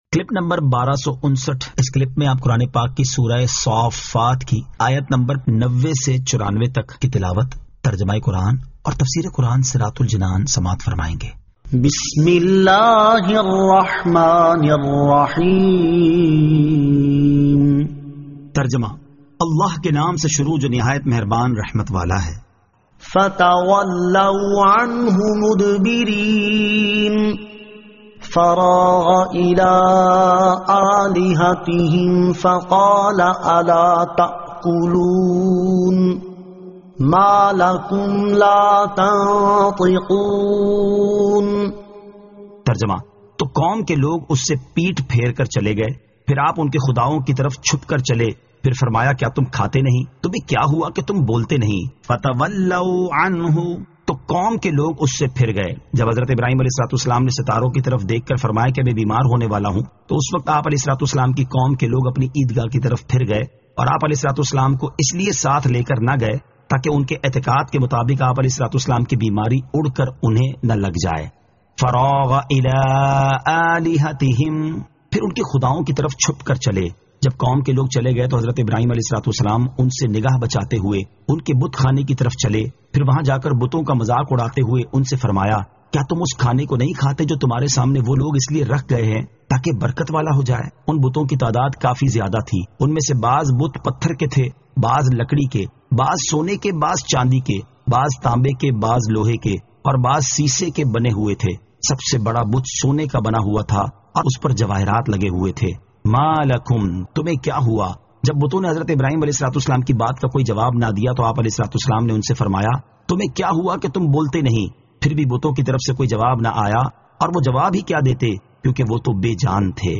Surah As-Saaffat 90 To 94 Tilawat , Tarjama , Tafseer